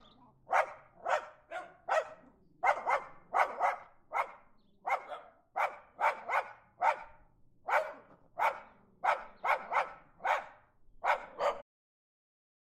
狗狗的叫声洛基编辑
描述：我的室友杰克罗素梗犬Loki在吠叫和叫声。
标签： 环境 - 声音的研究 树皮 杰克罗素 狗吠
声道立体声